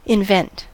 invent: Wikimedia Commons US English Pronunciations
En-us-invent.WAV